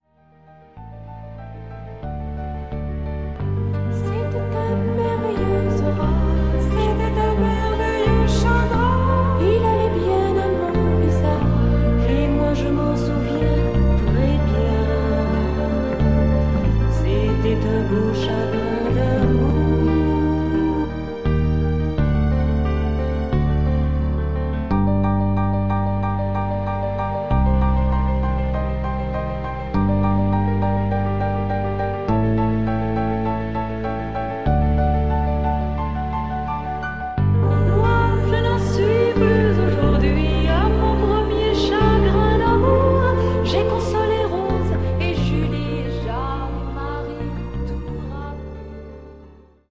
avec voix féminine...